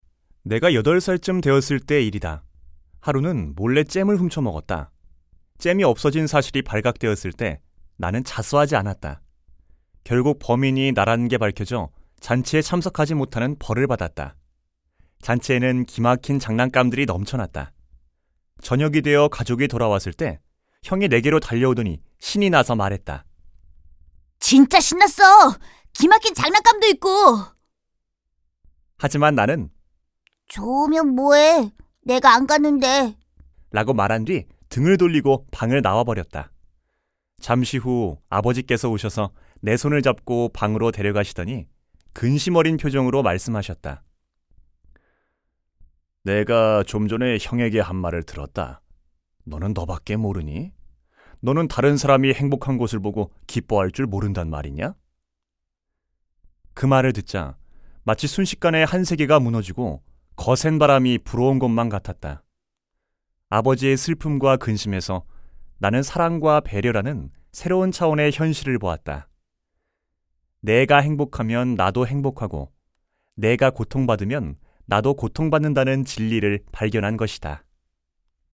047쪽-내레이션.mp3